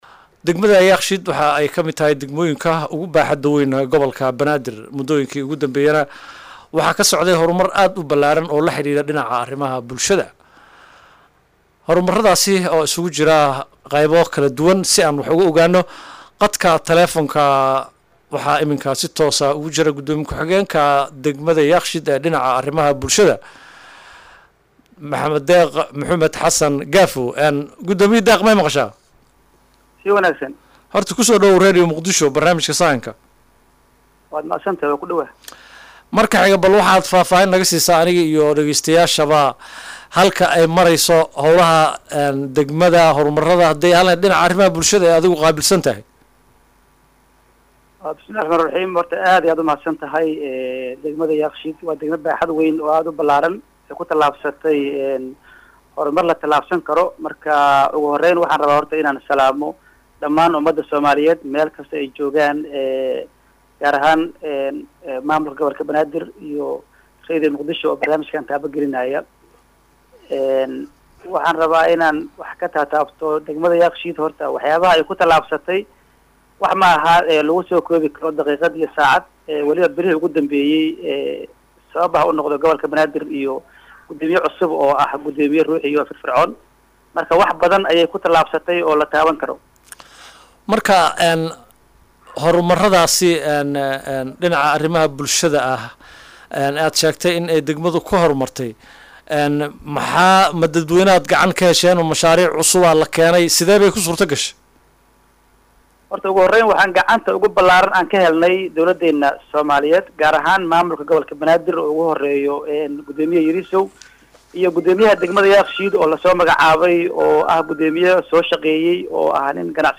Gudoomiye ku xigeenka maamulka degmada Yaaqshiid Deeq Muxumed Xasan Gaafoow oo wareysi siiyay Radio Muqdisho ayaa ka hadlay horumarrada ka jira degmada gaar ahaan dhinaca arrimaha bulshada.